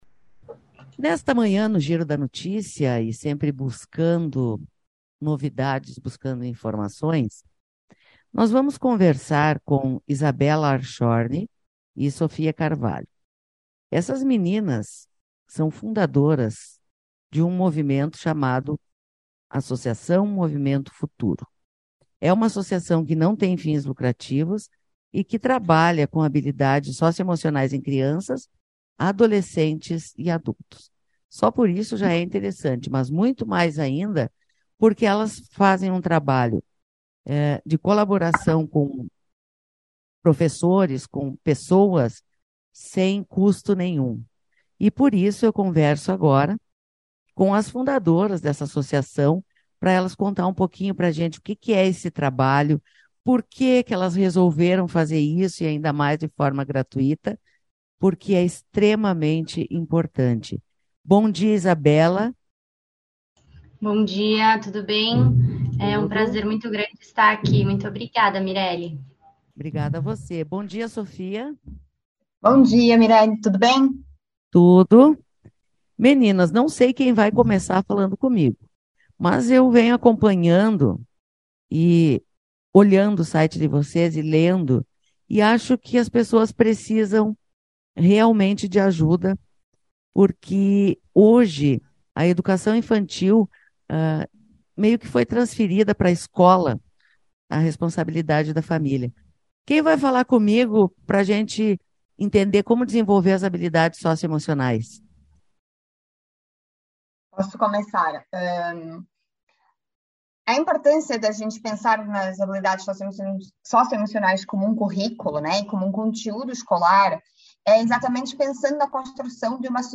Entrevista: Associação Movimento Futuro - instituição lançou e-books gratuitos para capacitação de professores